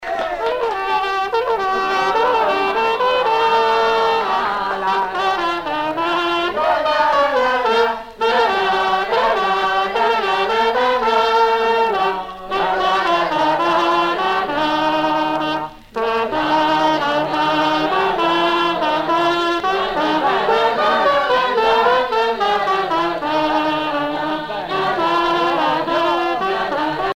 Airs traditionnels de noces poitevine - Air de valse
Pièce musicale éditée